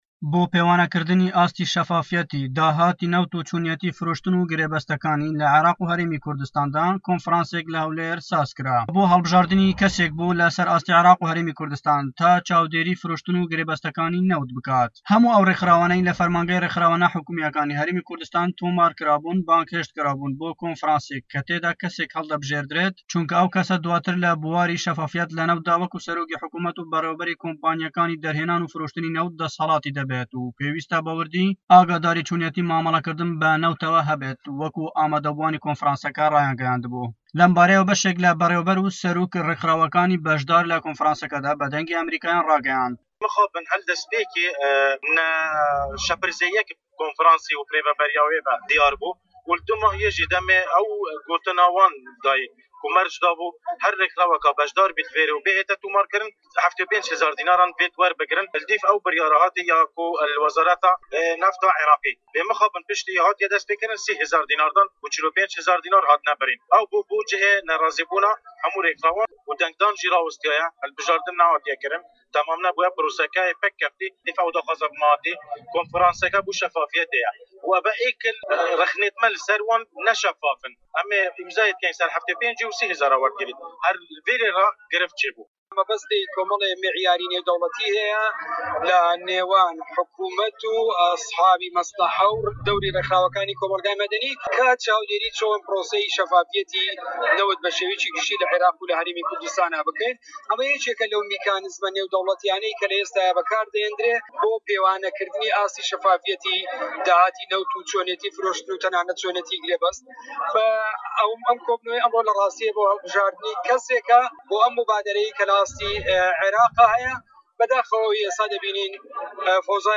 ڕاپۆرت - کۆنفڕانسی گرێبەستی نەوت